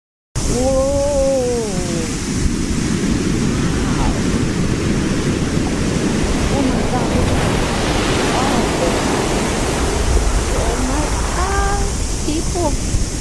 Tsunami Destroys Quiet Beach Town sound effects free download